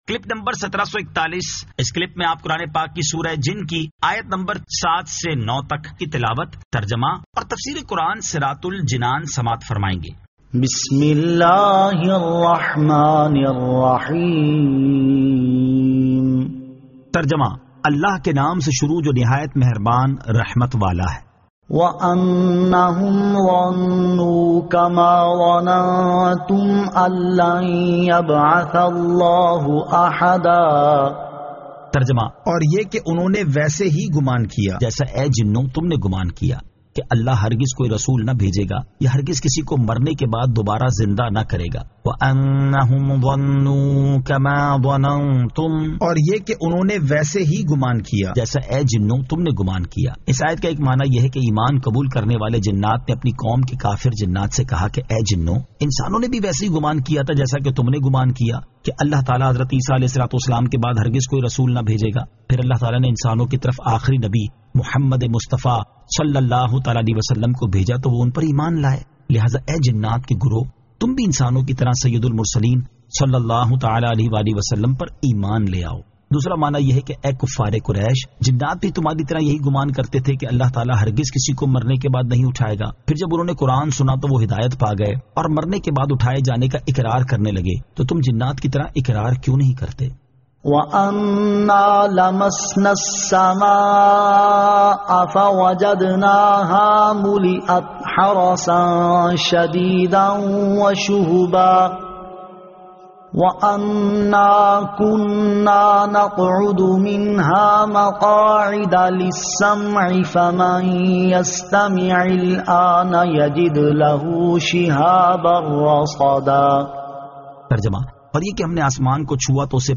Surah Al-Jinn 07 To 09 Tilawat , Tarjama , Tafseer